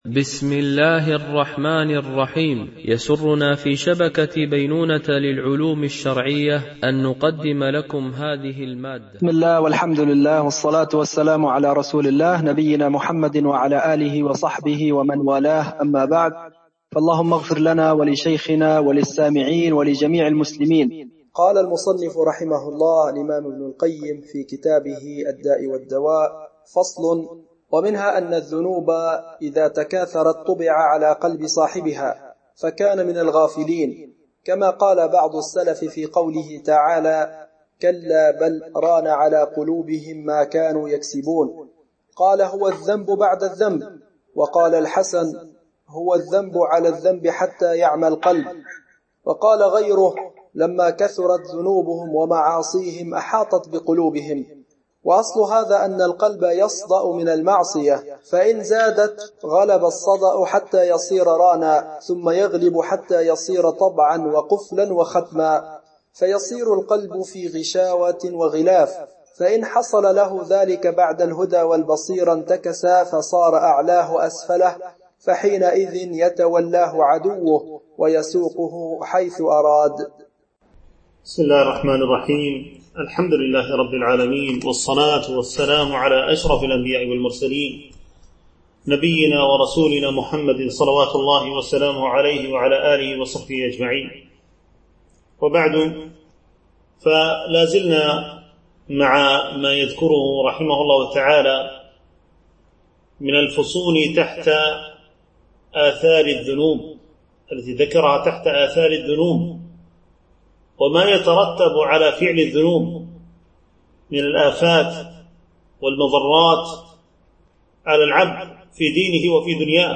شرح كتاب الداء والدواء ـ الدرس 15